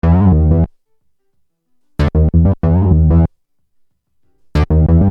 Bass 13.wav